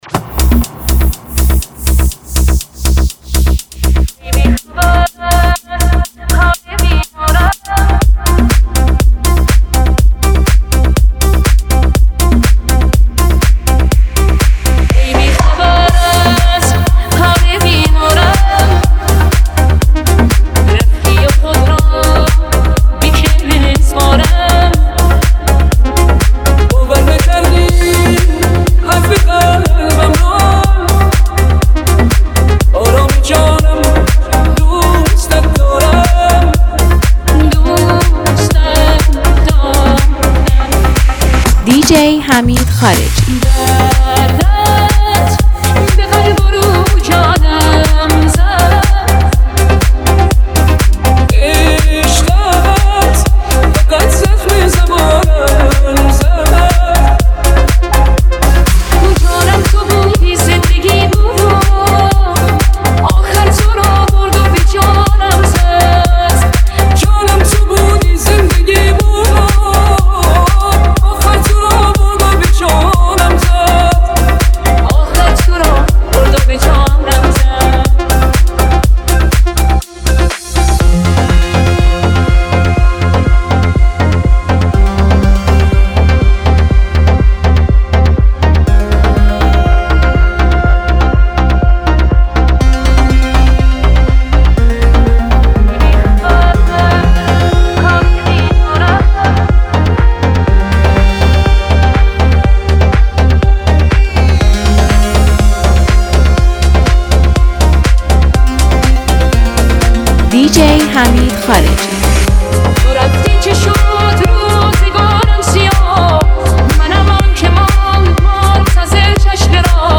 شاهکار هوش مصنوعی